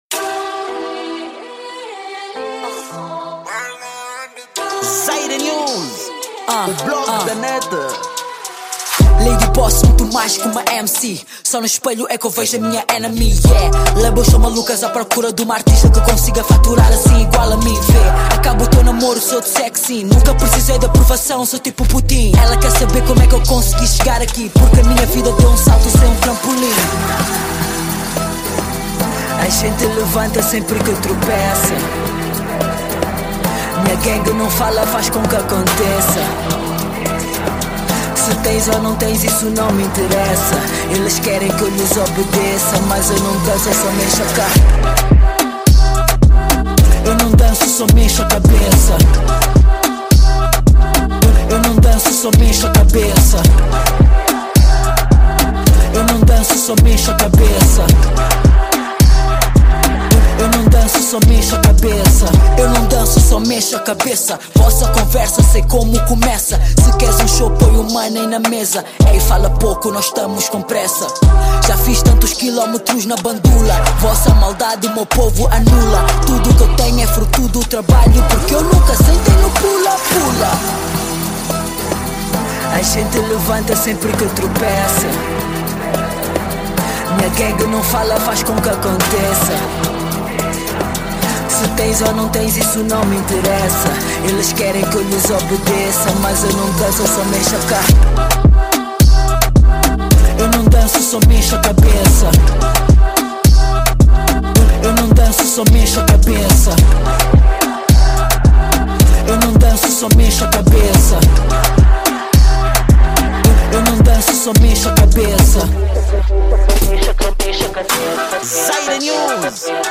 Gênero: Reggaeton